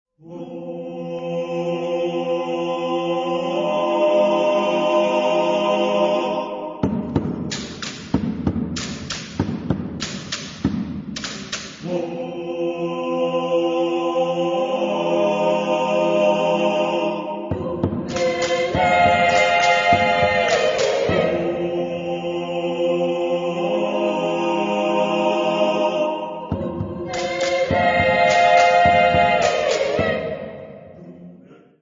Genre-Style-Form: Sacred ; Secular ; Traditional
Mood of the piece: mystical ; rhythmic ; mysterious
Type of Choir: SSAATTBB  (8 mixed voices )
Instrumentation: Percussion  (2 instrumental part(s))
Instruments: Claves (1) ; Tenor drum
Tonality: modal
Discographic ref. : 12ème Festival des Choeurs Lauréats de Vaison-la-Romaine 2004